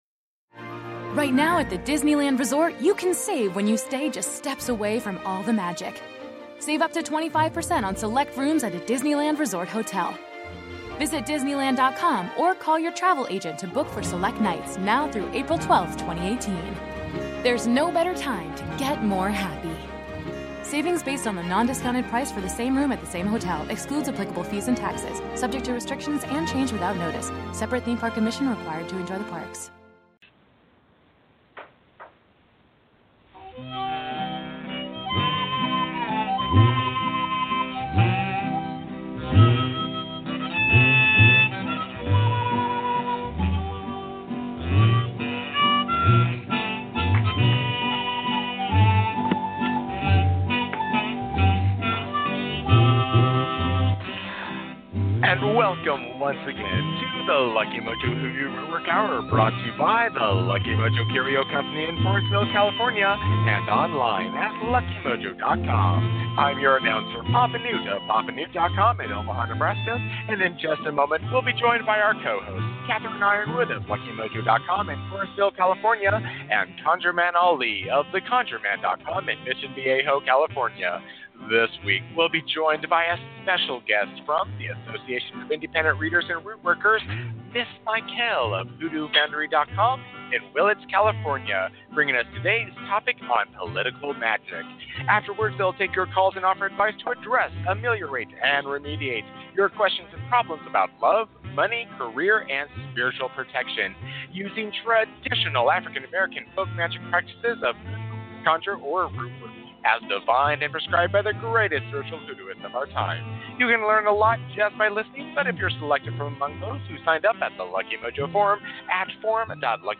We began this show with a Discussion Panel focussed on the topic of Political Magic.